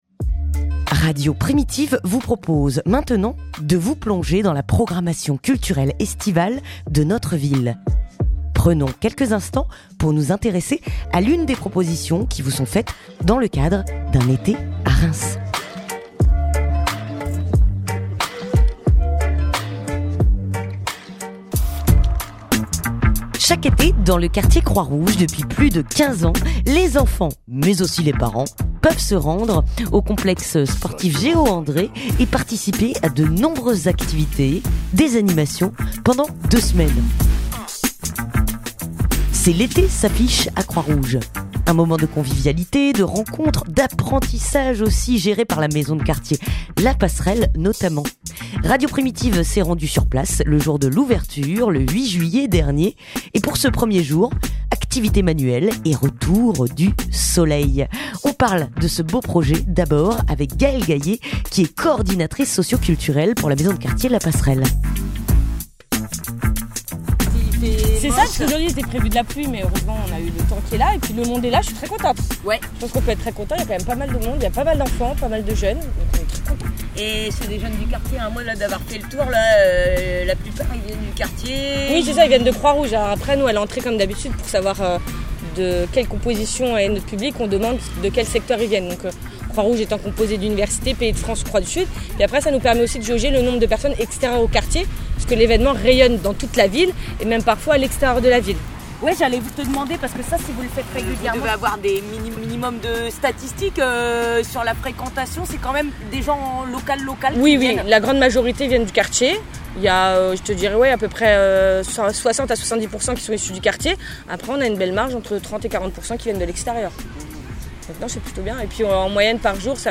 Radio Primitive vous emmène à l'ouverture de la nouvelle édition de l'Eté s'affiche à Croix-Rouge. Un rendez-vous estival pour les familles et une ouverture spéciale pour les adolescents.